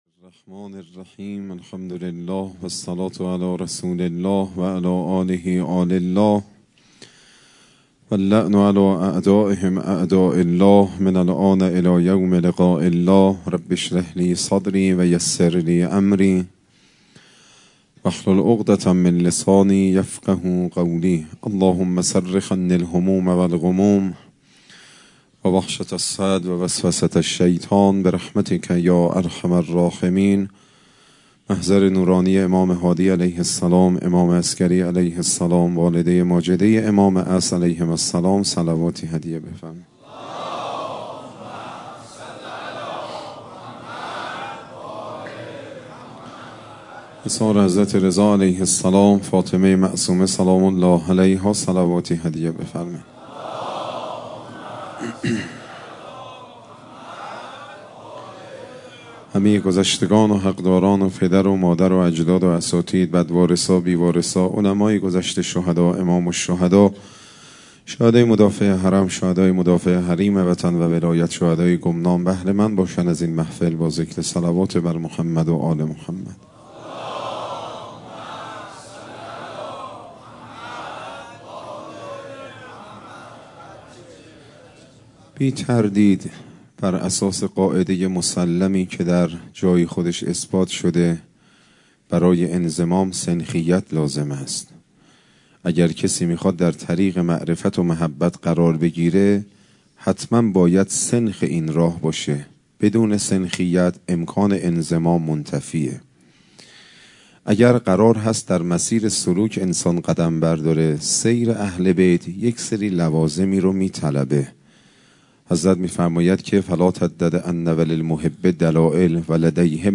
سخنرانی شب پنجم محرم 1402